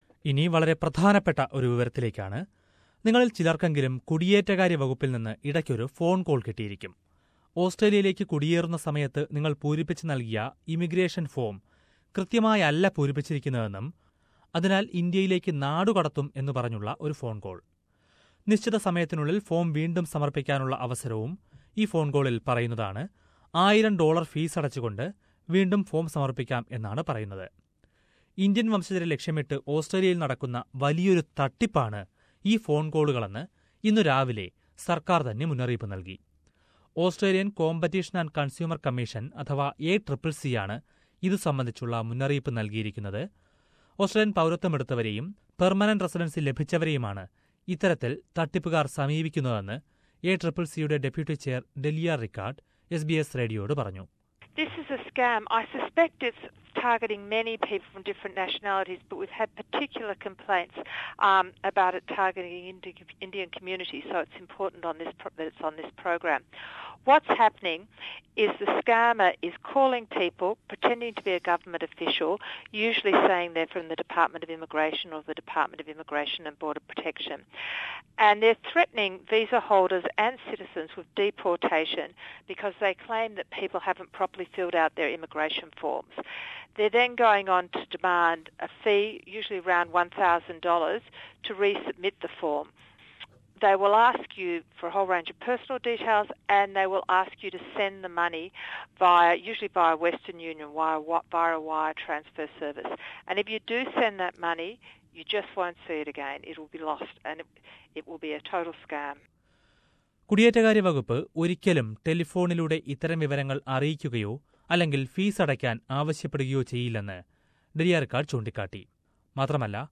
The Australian Competition and Consumer Commission has urged visa holders and Australian citizens to hang up on fraudulent phone calls from scamsters. It is warned that Indian orgin people living in Australia are the main targets of thes scamsters. Listen to a report on that.